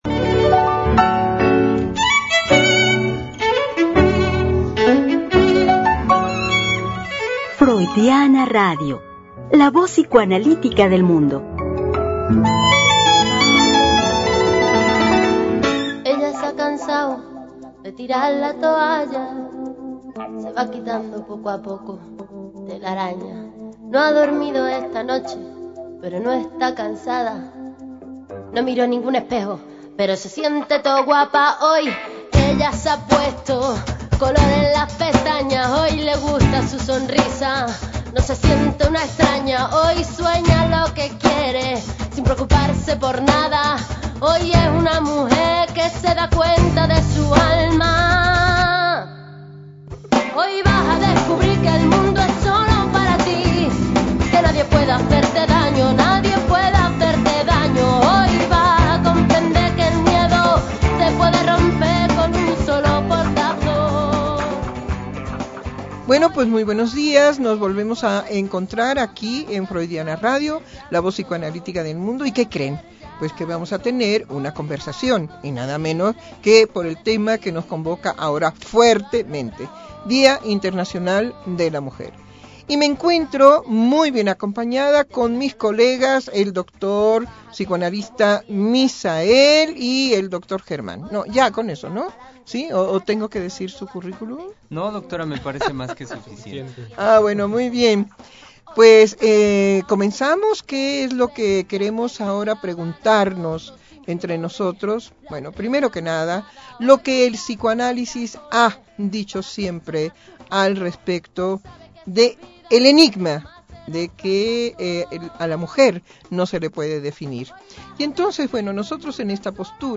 Conversación con los Psicoanalistas